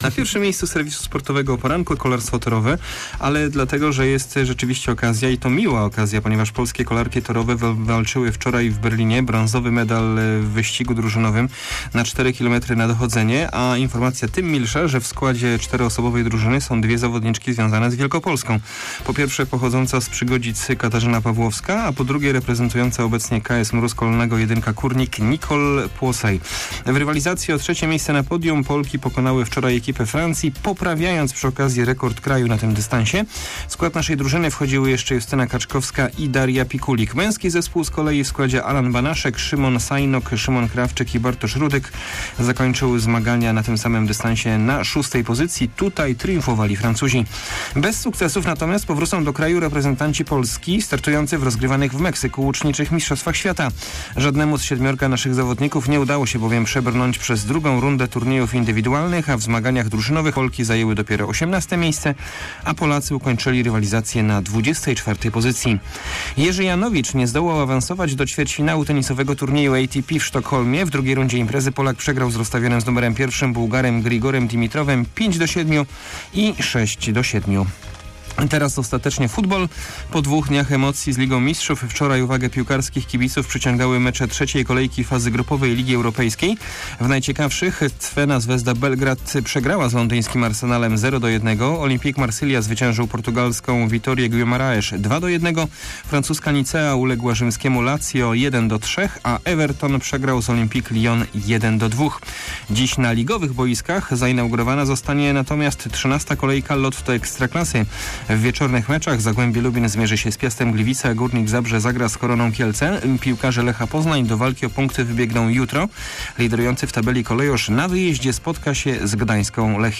20.10 serwis sportowy godz. 7:40